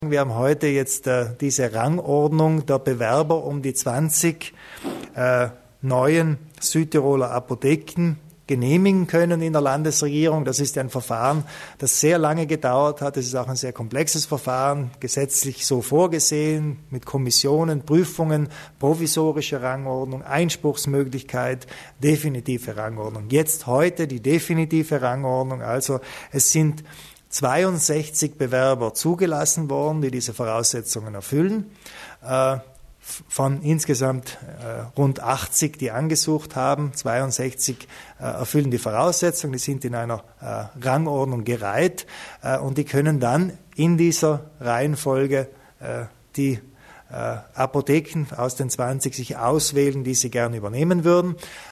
Landeshauptmann Kompatscher zur Neuausschreibung von Apotheken